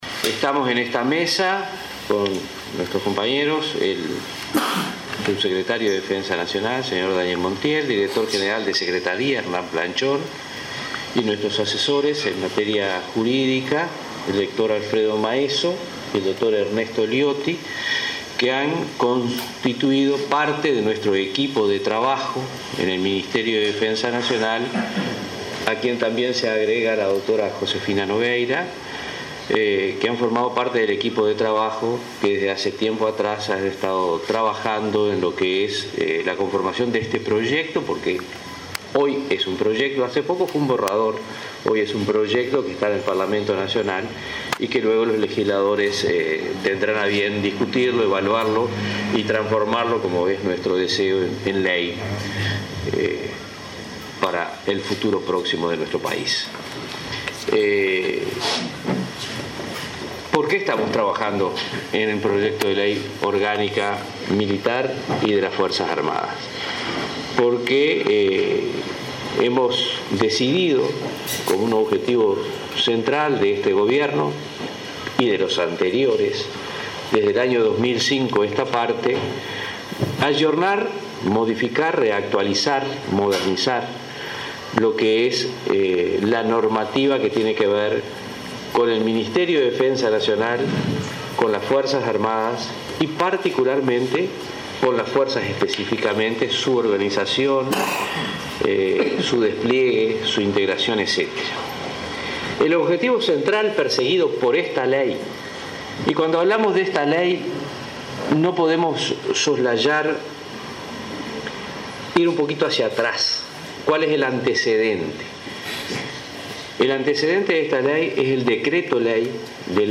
Gobierno elevó al Parlamento el proyecto de reforma de la ley orgánica militar y de las Fuerzas Armadas 24/08/2018 Compartir Facebook X Copiar enlace WhatsApp LinkedIn El principio de complementariedad entre Ejército, Fuerza Aérea y Armada, el impulso de la profesionalización de efectivos a través del estatuto, el sistema de ascenso, la disminución de la cantidad de comandantes y generales, así como la nueva reglamentación de tribunales de honor, son algunos de los ítems que abarca el proyecto de ley orgánica militar y de las Fuerzas Armadas, explicó el ministro de Defensa, Jorge Menéndez.